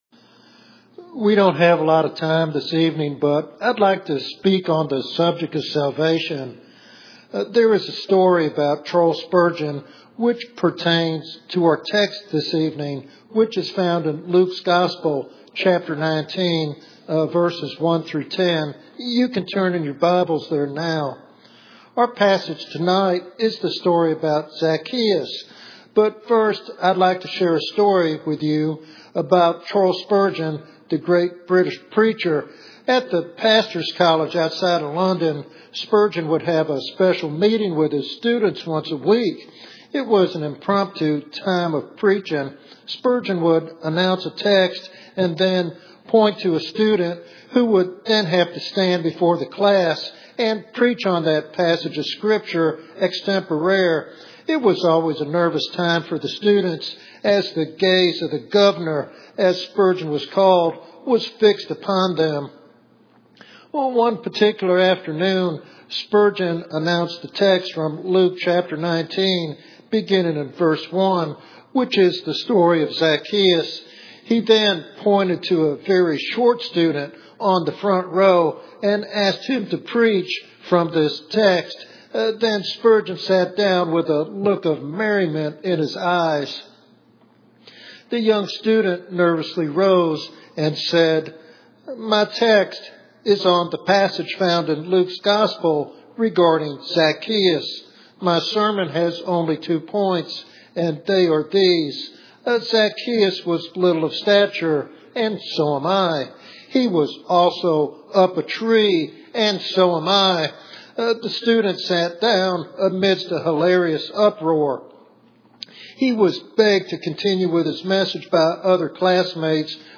In this expository sermon